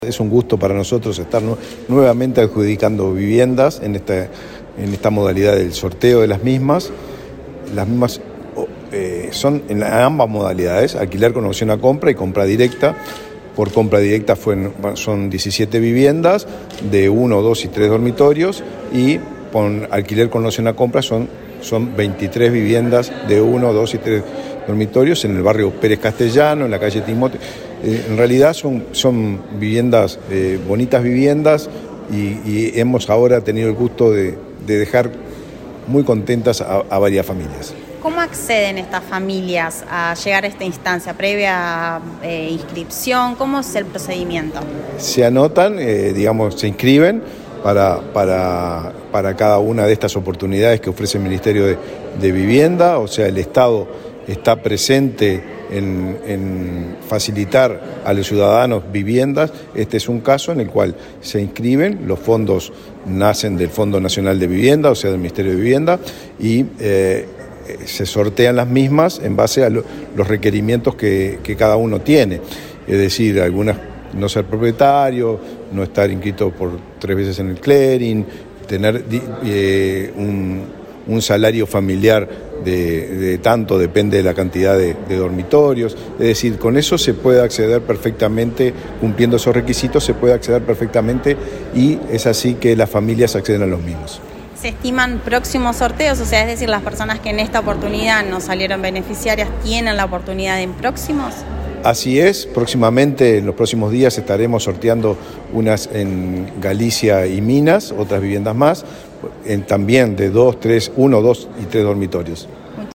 Declaraciones del ministro de Vivienda, Raúl Lozano
El ministro de Vivienda, Raúl Lozano, dialogó con Comunicación Presidencial, luego de participar en el sorteo entre inscriptos para acceder a una